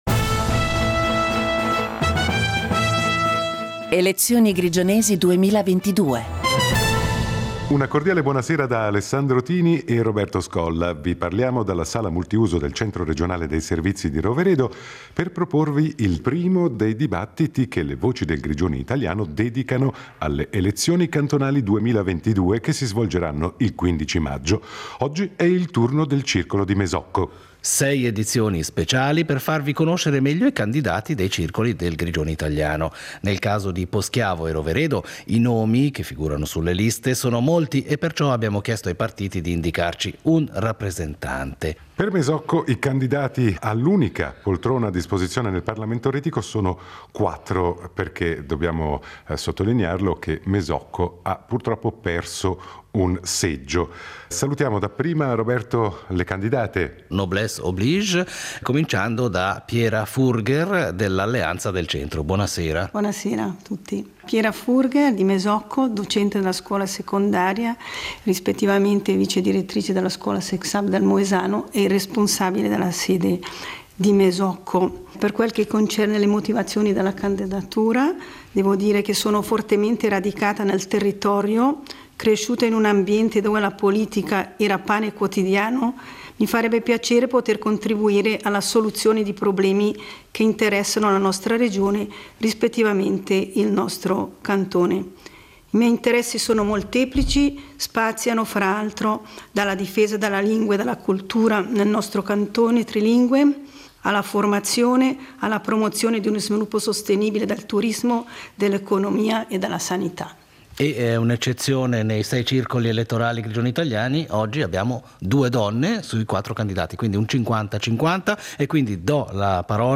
Dibattito in vista delle elezioni retiche del 15 maggio 2022